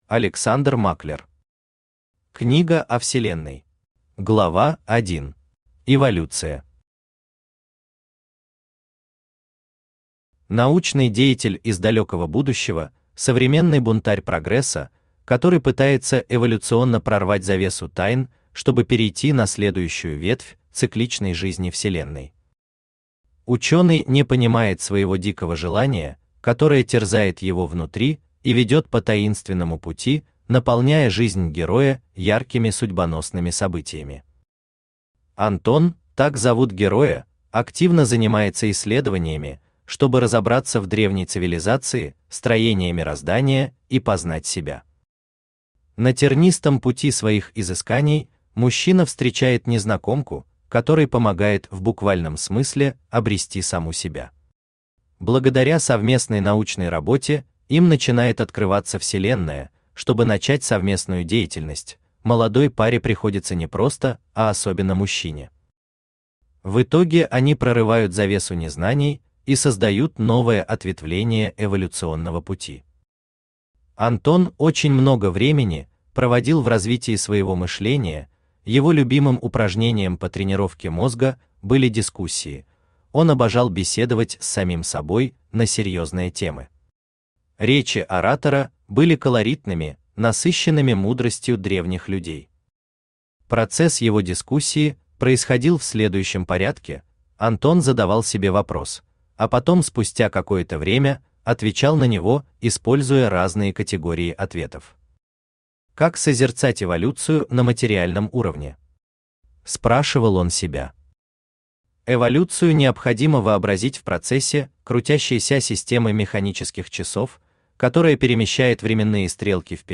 Аудиокнига Книга о Вселенной | Библиотека аудиокниг
Aудиокнига Книга о Вселенной Автор Александр Германович Маклер Читает аудиокнигу Авточтец ЛитРес.